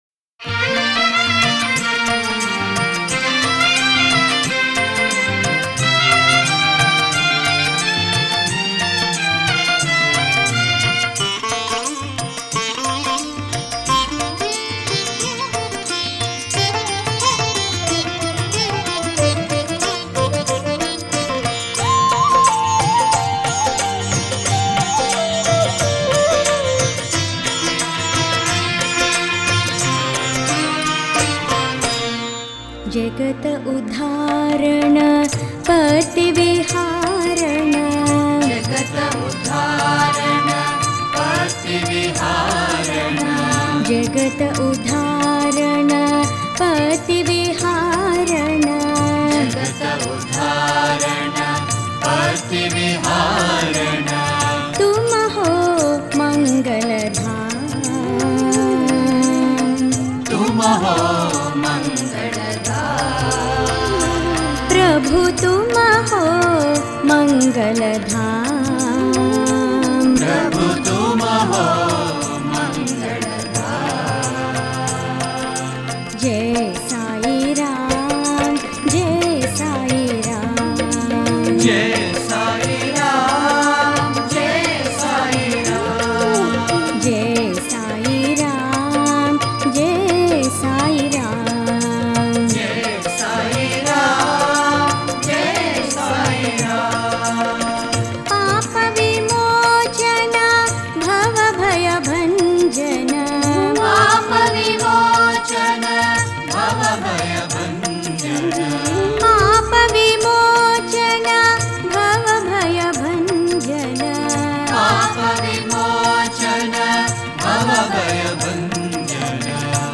Author adminPosted on Categories Sai Bhajans